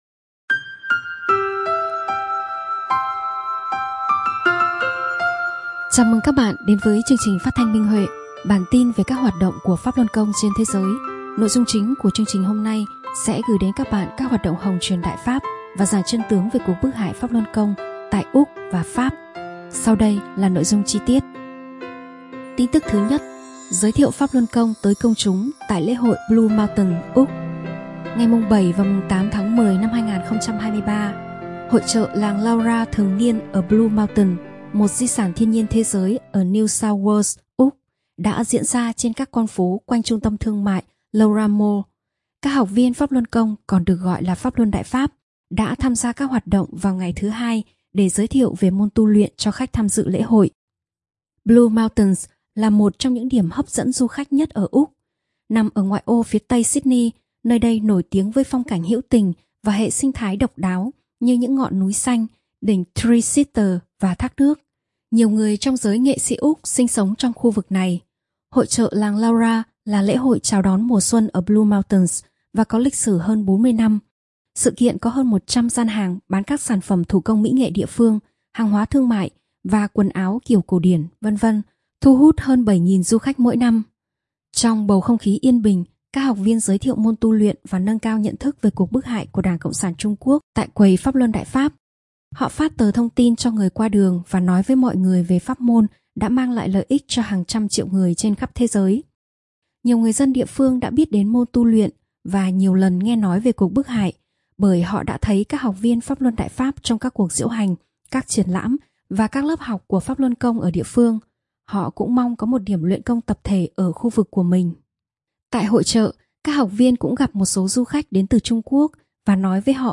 Chương trình phát thanh số 81: Tin tức Pháp Luân Đại Pháp trên thế giới – Ngày 23/10/2023